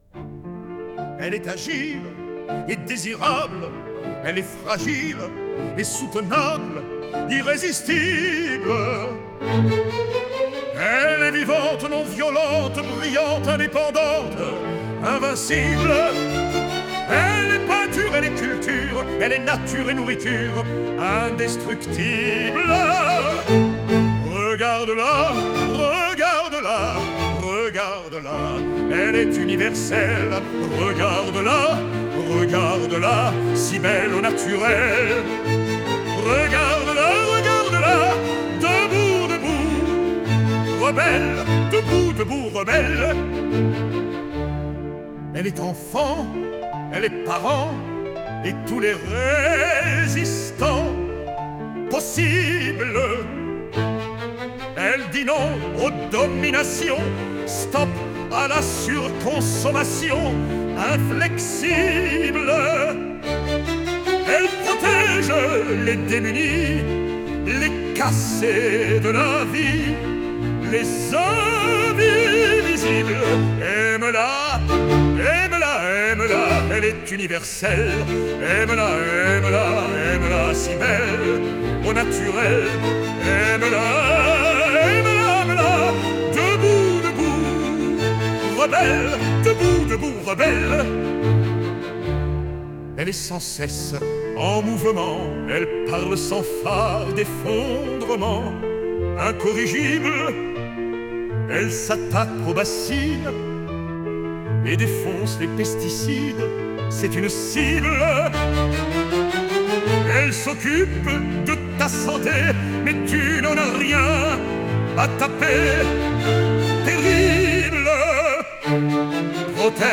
Debout-rebelles-Hymne-Chanson-Lyrique-Mars-2025.mp3